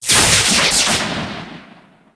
energy.ogg